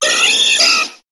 Cri de Persian dans Pokémon HOME.